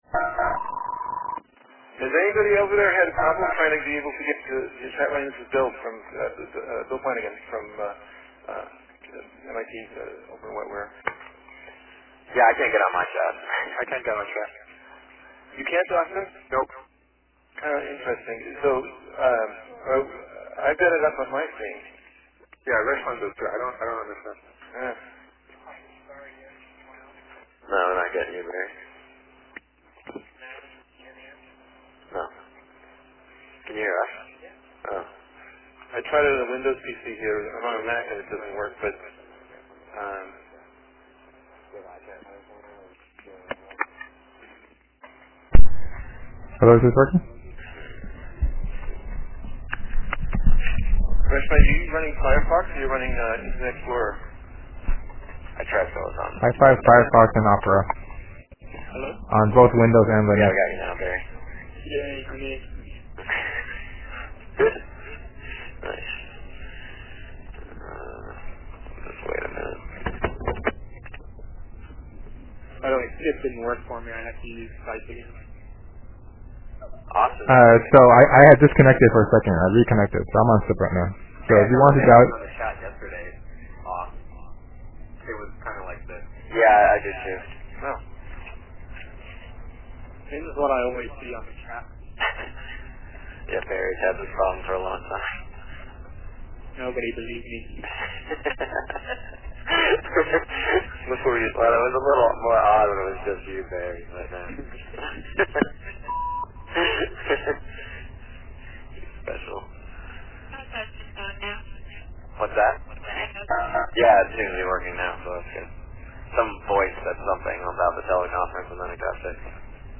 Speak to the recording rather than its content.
audio from the meeting